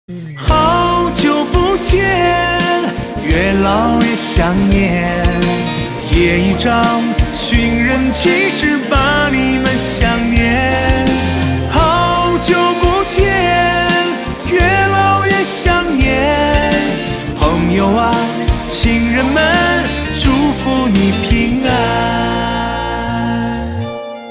M4R铃声, MP3铃声, 华语歌曲 90 首发日期：2018-05-14 11:41 星期一